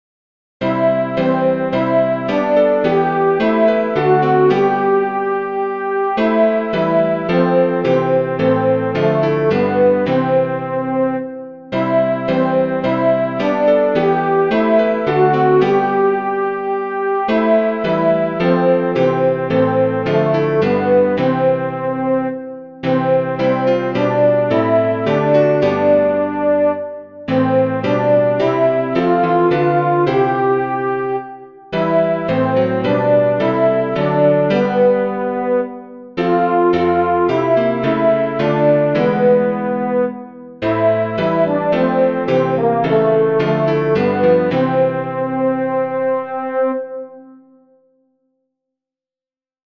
ein_feste_burg-alto1.mp3